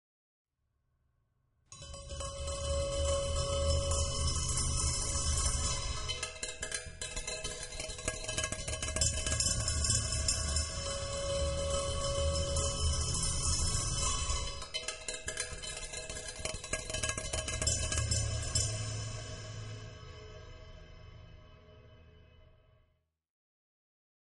banging on trumpet
56932-banging-on-trumpet.mp3